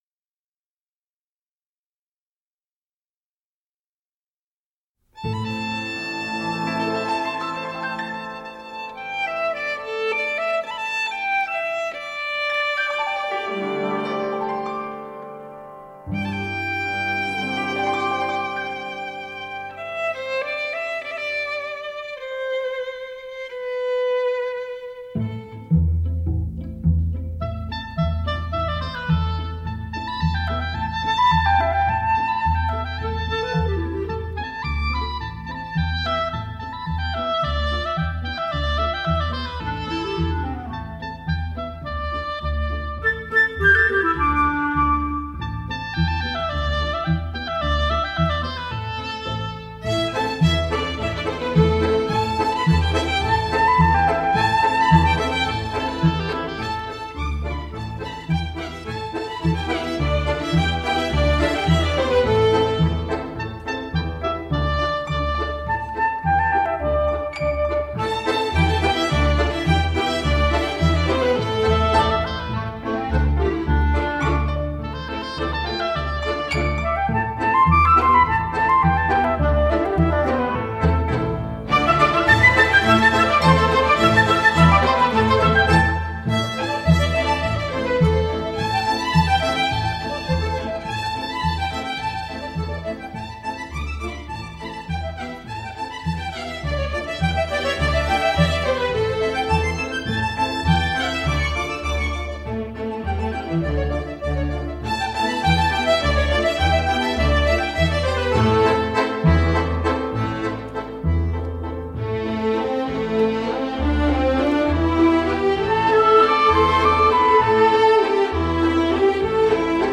青海民歌